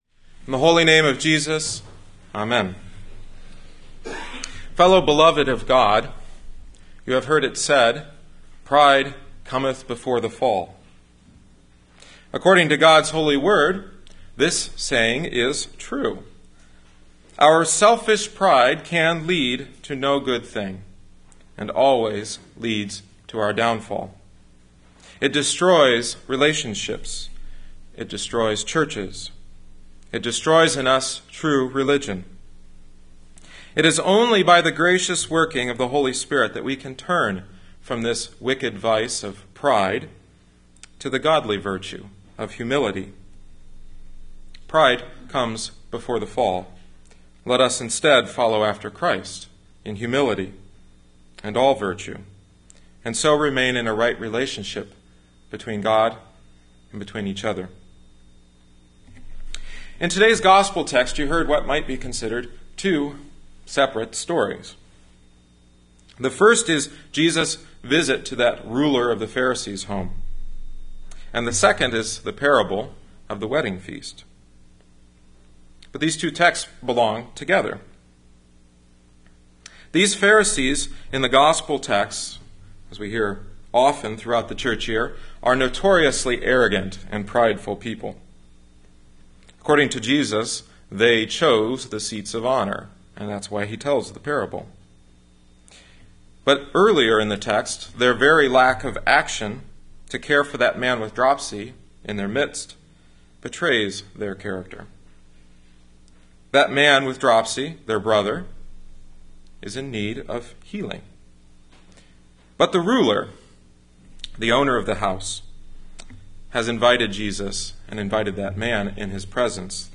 Grace Lutheran Church – Dyer, Indiana